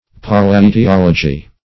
palaetiology \palaetiology\, Palaetiology \Pa*l[ae]`ti*ol"o*gy\,